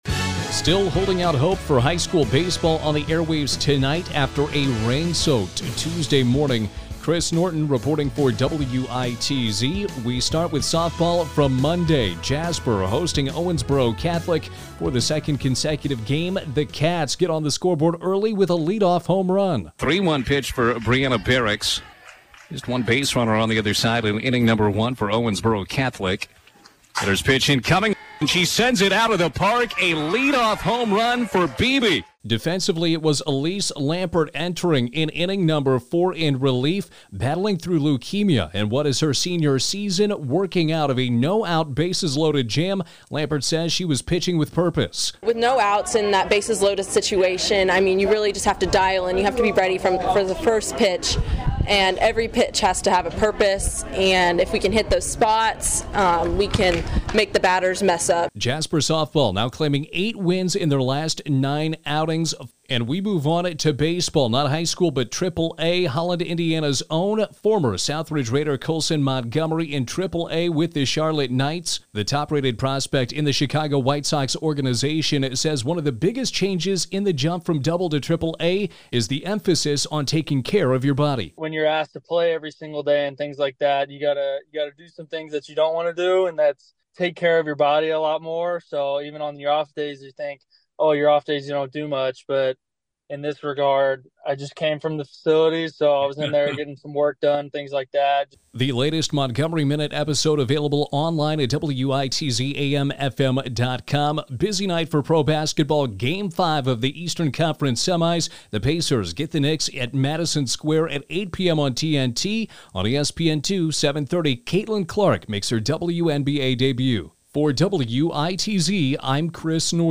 Best Radio Sports Show or Sports StoryWITZ-FM (Jasper) – Senior Pitching with Extra Purpose